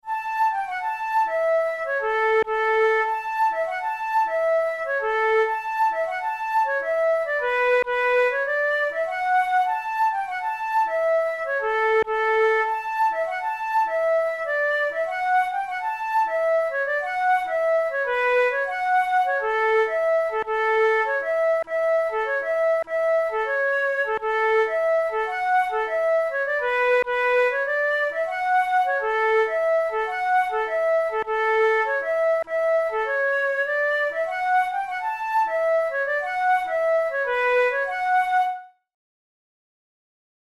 Celtic Music, Contemporary, Strathspeys
Strathspey